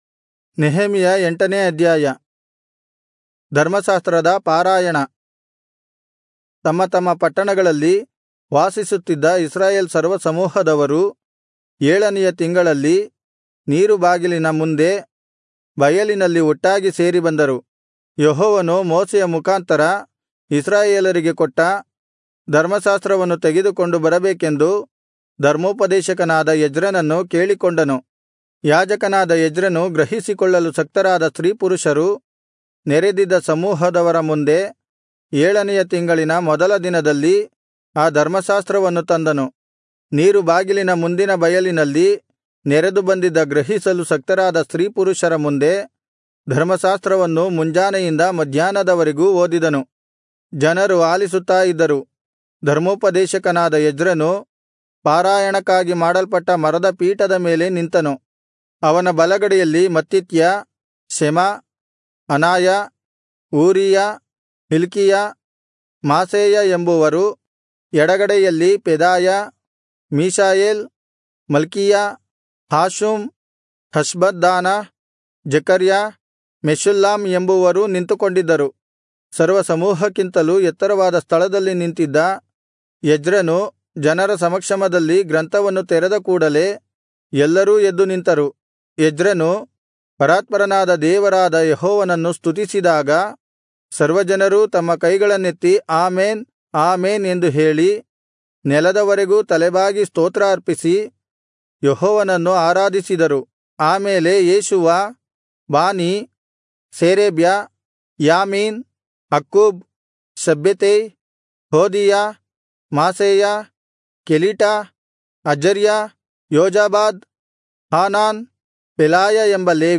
Kannada Audio Bible - Nehemiah 1 in Irvkn bible version